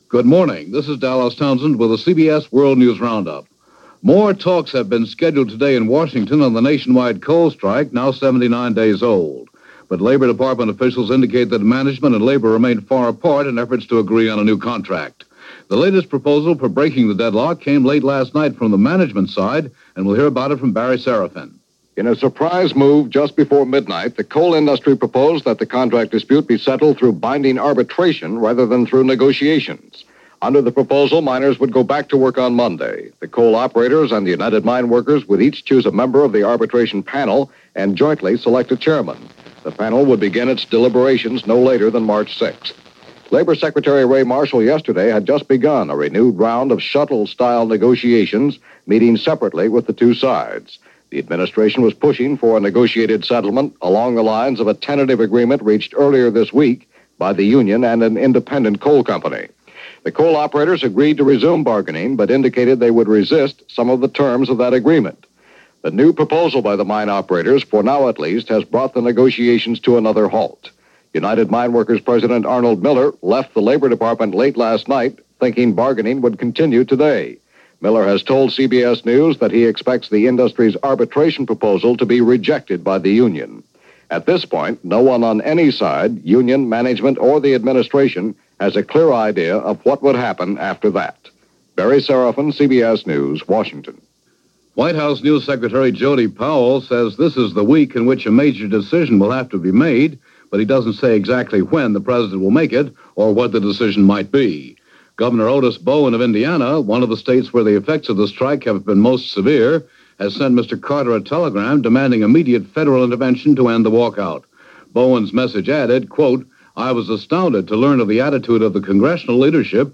February 22, 1978 - The 79 Day Old Coal Strike - Debating The Panama Canal Treaty - A Funeral In Cairo - News for the day - Past Daily